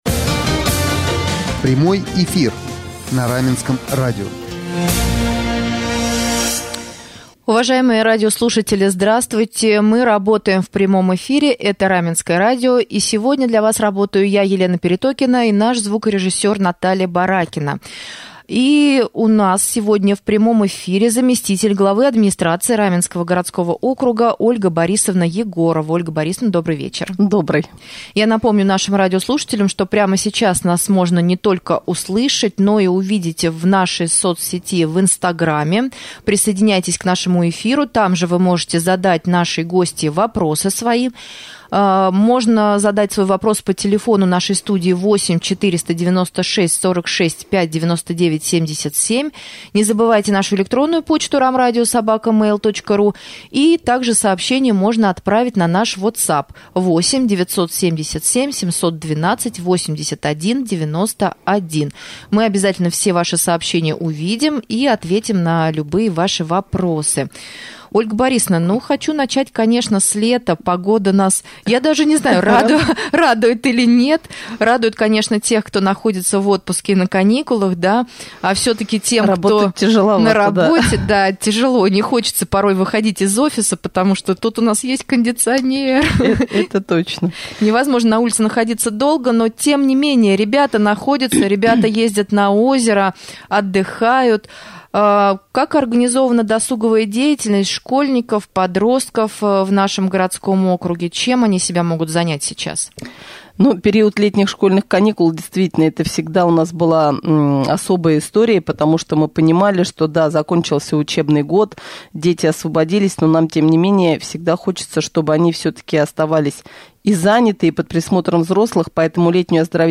Гостем прямого эфира на Раменском радио 23 июня стала заместитель главы администрации Раменского округа Ольга Борисовна Егорова.